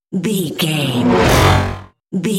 Whoosh electronic fast
Sound Effects
Atonal
Fast
bright
futuristic
intense
whoosh